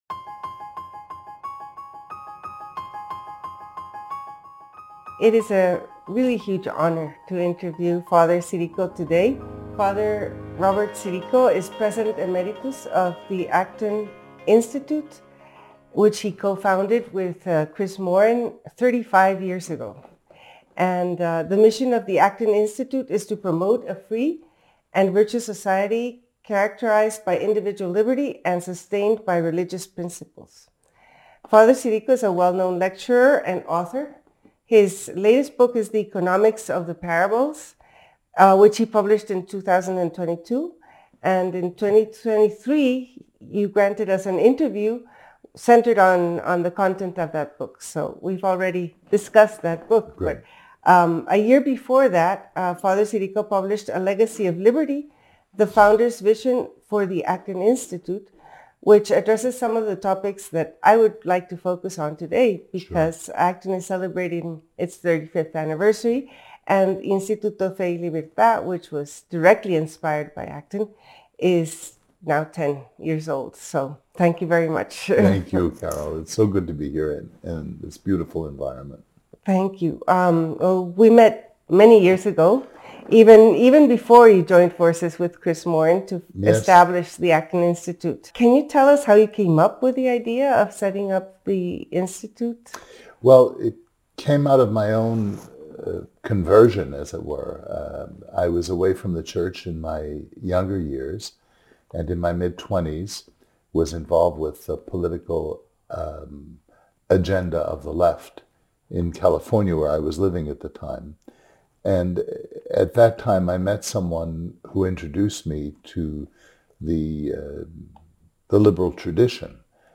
280 | A Dialogue on Institutional Stewardship, with Rev. Robert Sirico